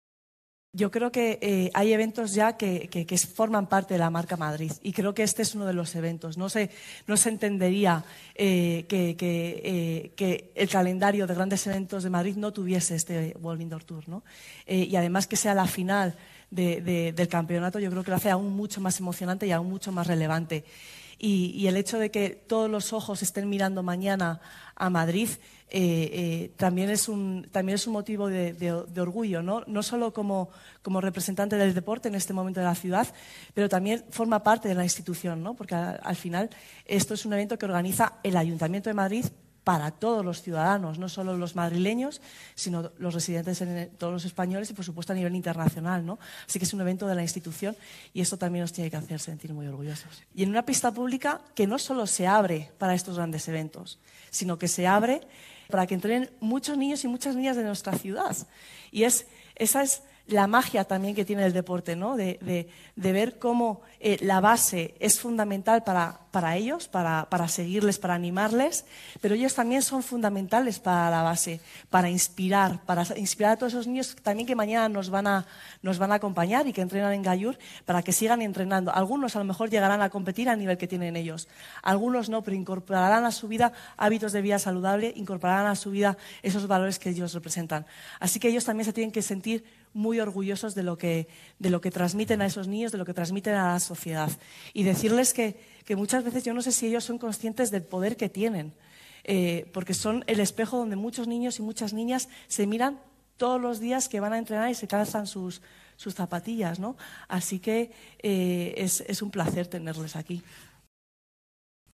La concejala delegada de Deporte, Sofía Miranda, ha presentado esta mañana el Indoor Tour Gold Madrid 22 que se celebra este miércoles, 2 de marzo, desde las 19:00 h, en el Centro Deportivo Municipal Gallur.